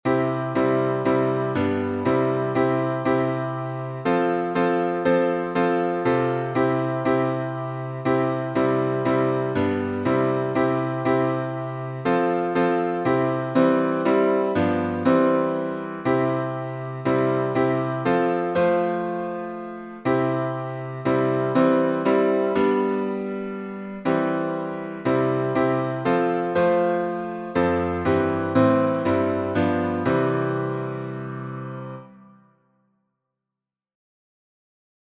#5109: Jesus Loves Me — C major, four four | Mobile Hymns